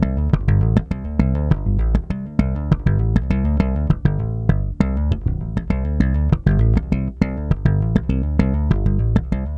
SONS ET LOOPS GRATUITS DE BASSES DANCE MUSIC 100bpm
Basse dance 6